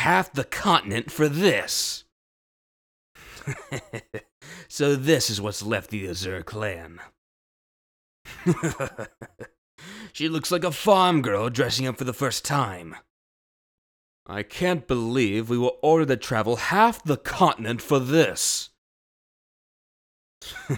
Voice: Deep, slightly gruff